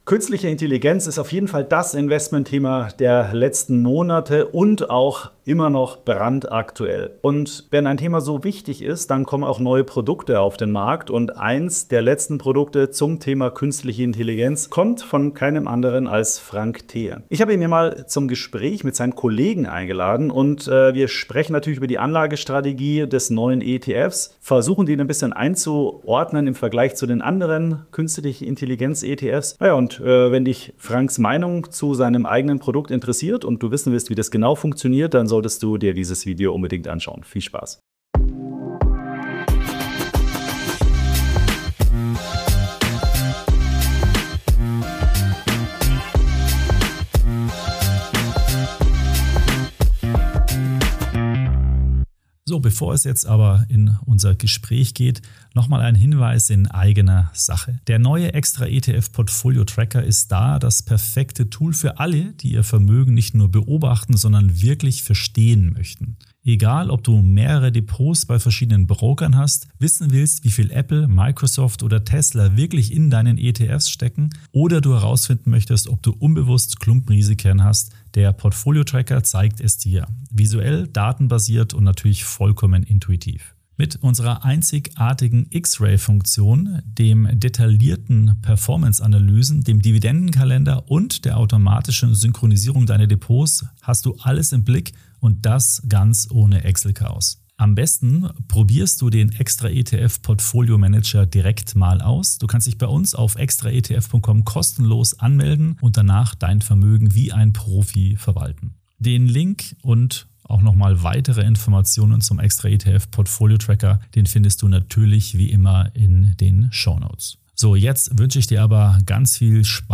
Im Interview geht es um den Unterschied zwischen General AI und Narrow AI, die Rolle von Chips, Rechenzentren und Energie sowie die Frage, ob KI bereits überbewertet ist. Außerdem klären wir, wie sich der ETF von klassischen Tech- und KI-ETFs unterscheidet und woran Anleger seinen Erfolg messen sollten.